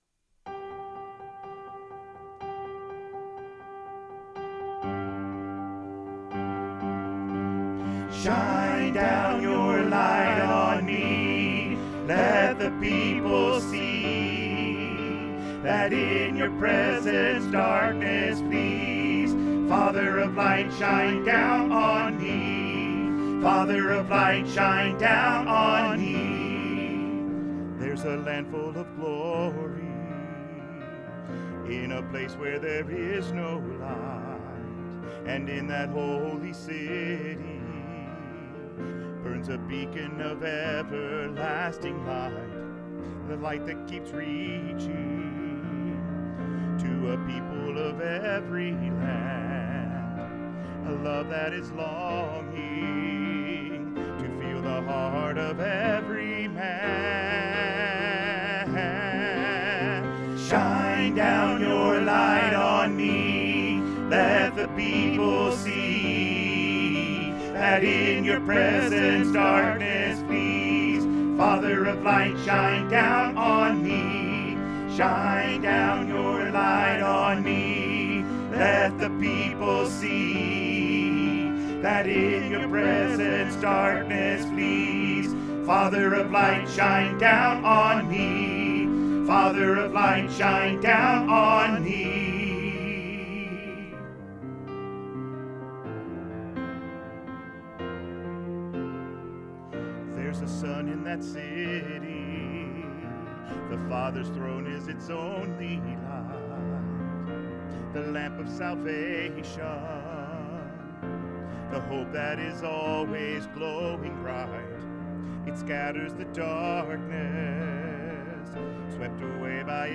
Choir Music Learning Recordings
Shine Down - Even Mix Even Mix of all 4 Parts